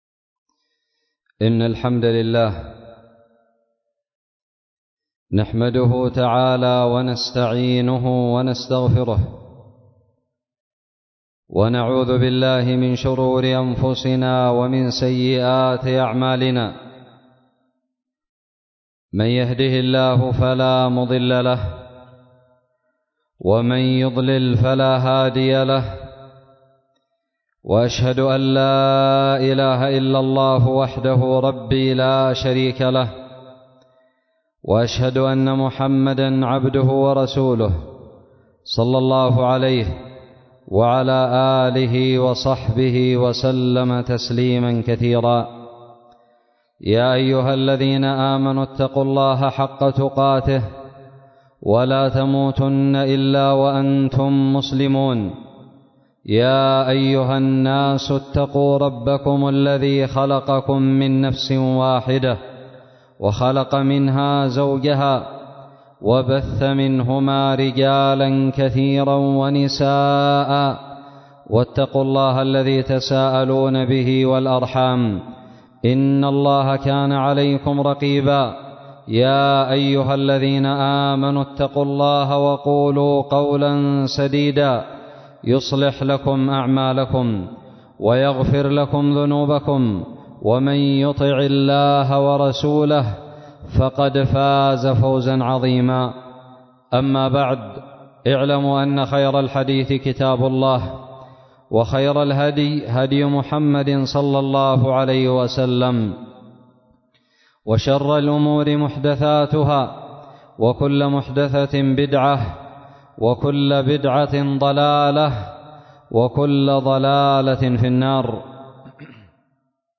خطب الجمعة
ألقيت بدار الحديث السلفية للعلوم الشرعية بالضالع في 15 ذو القعدة 1442هـ